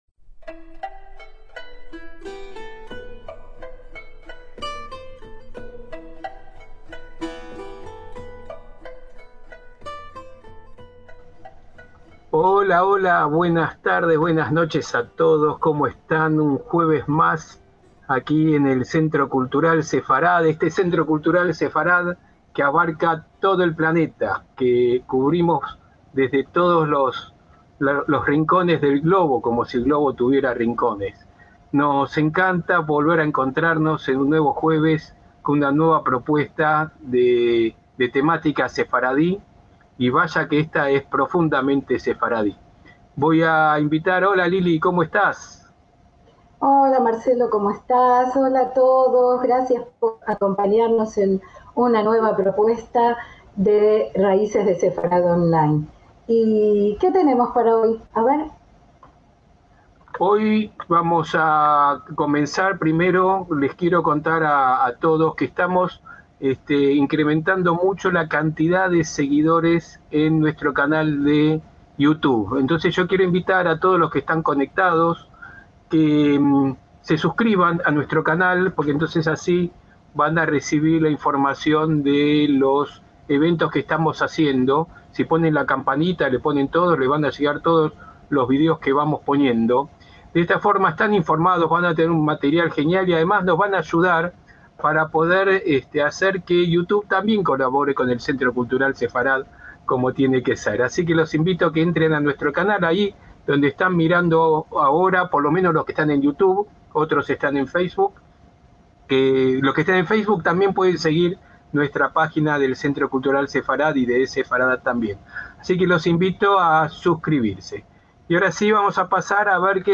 ACTOS "EN DIRECTO"